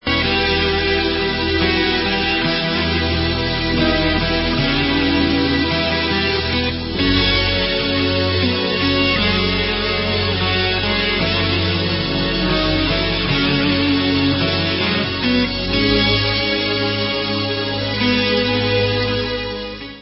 live 2001